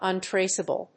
/ʌˈntresʌbʌl(米国英語), ʌˈntreɪsʌbʌl(英国英語)/